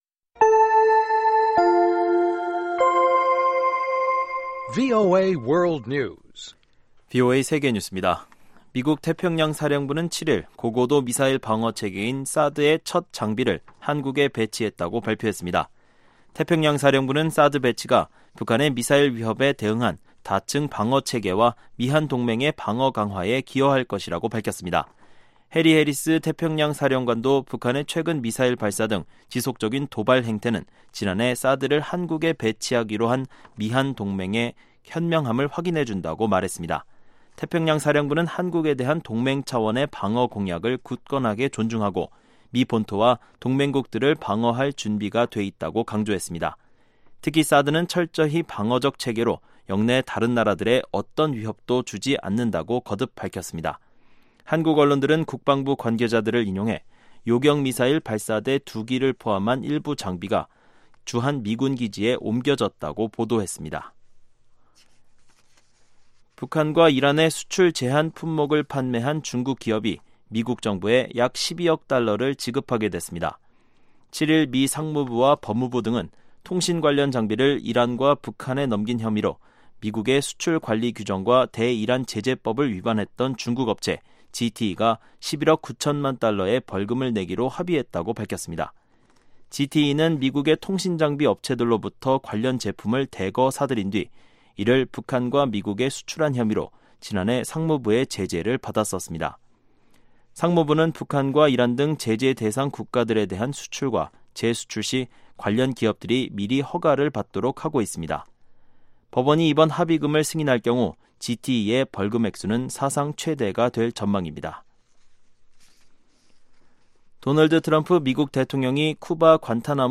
VOA 한국어 방송의 아침 뉴스 프로그램 입니다.